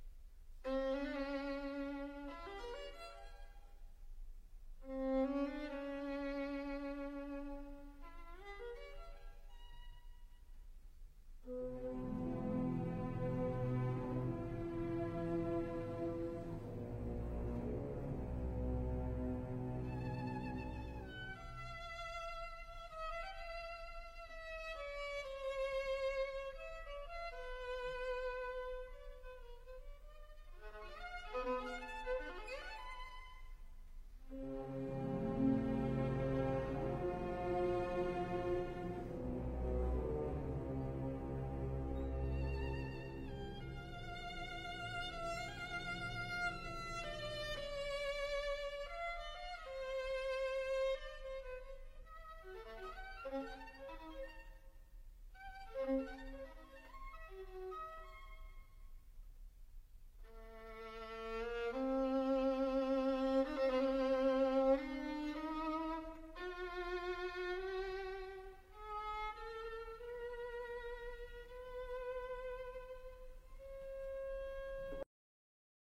Violin: R. Strauss: Ein Heldenleben, Reh. 22-32 (Concertmaster Solo) – Orchestra Excerpts
Ein Heldenleben violin excerpt